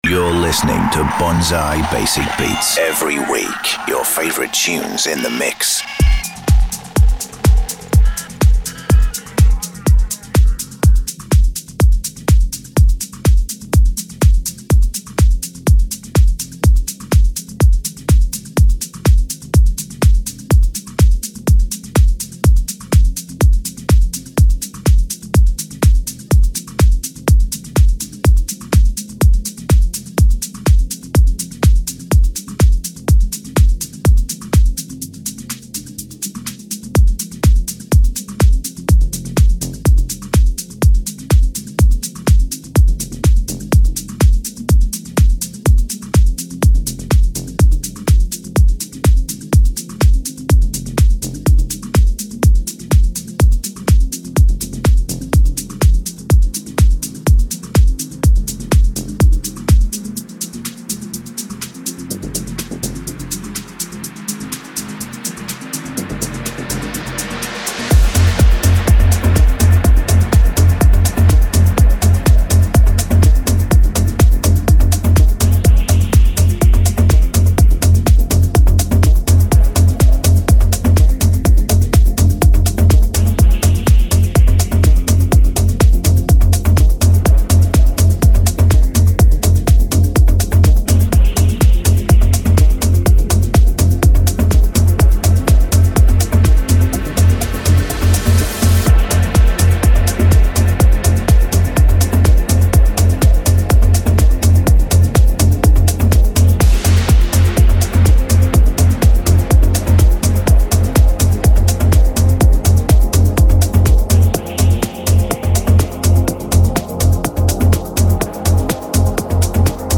superb journey-led progressive mix